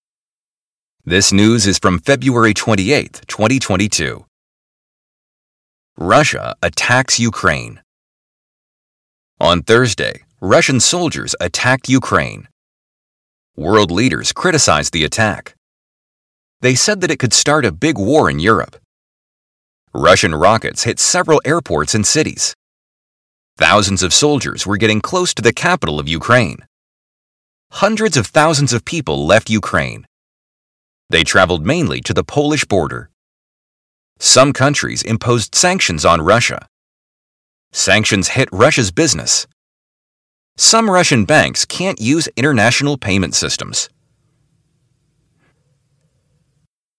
Lesson 6 - Shadowing